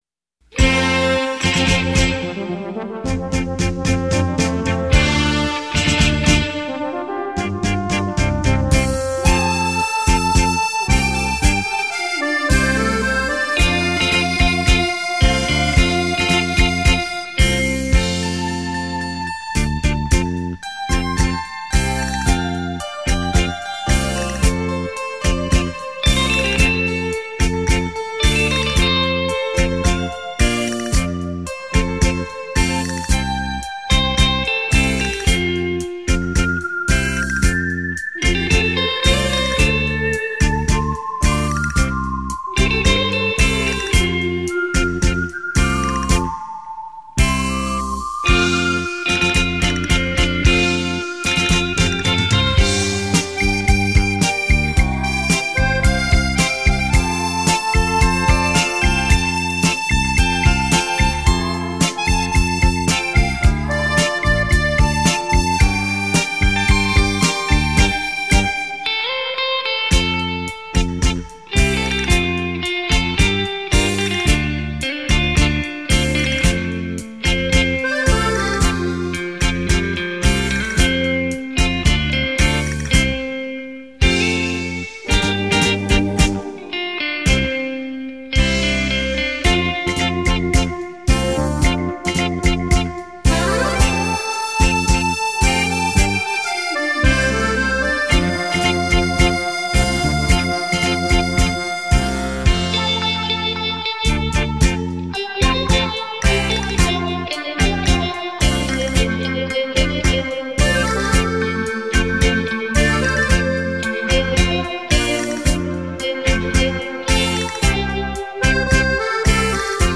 音乐 舞曲 伴唱三用